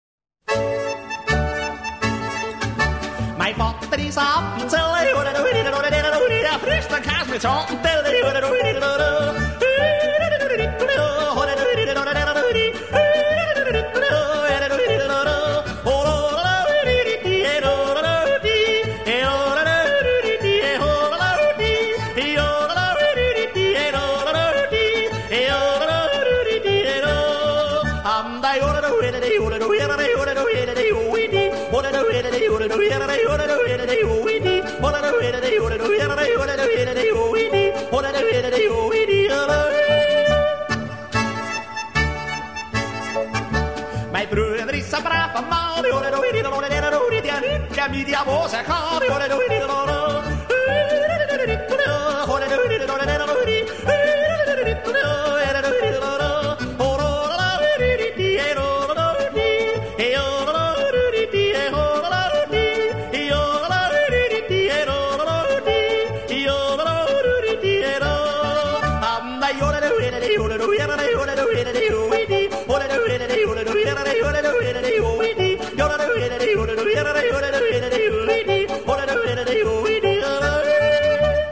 смешные
Хоть она и на немецком,но очень позитивна)